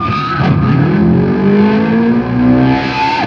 DIVEBOMB16-R.wav